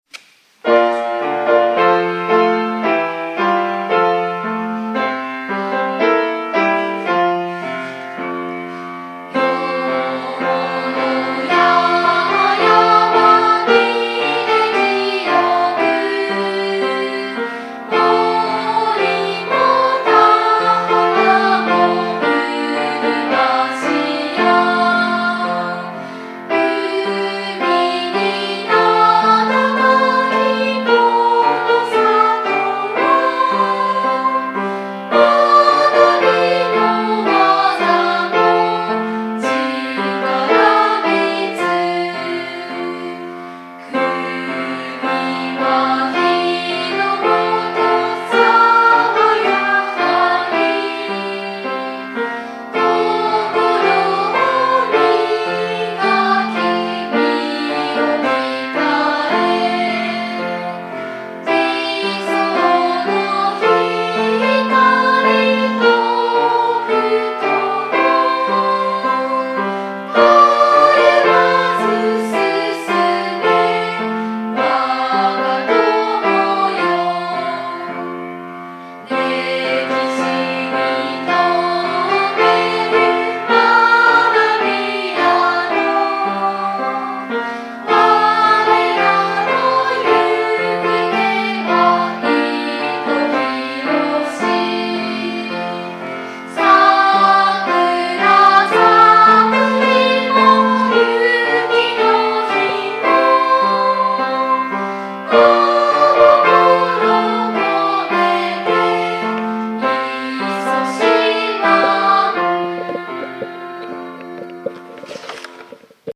校歌
※ 録音状況がよくありません。ノイズ等がありますのでご了承ください。